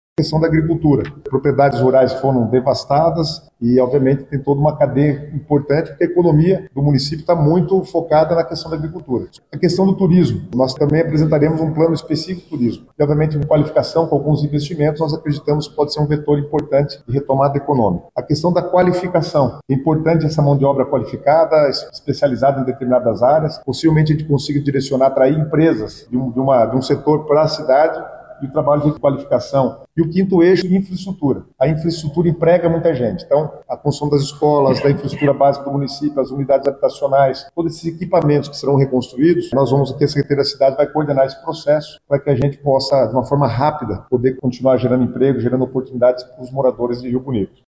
Guto Silva ainda falou dos outros eixos que farão parte do plano de Recuperação Econômica de Rio Bonito do Iguaçu.